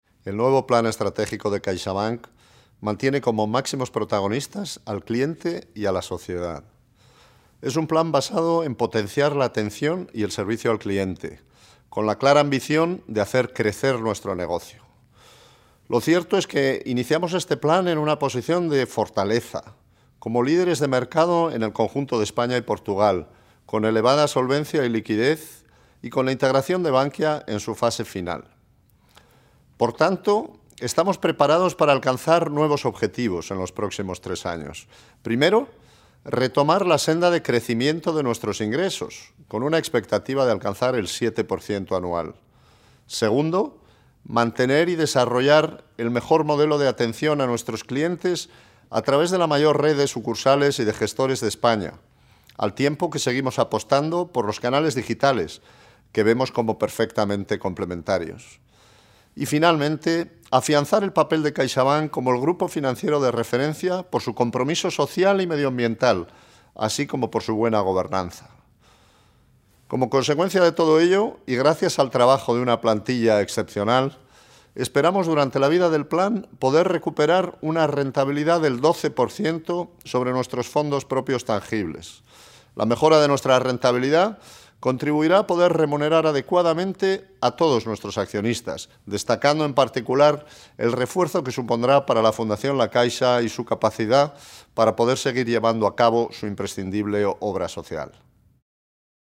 Audio_del_CEO_de_CaixaBank_Gonzalo_Gortazar_CAST.mp3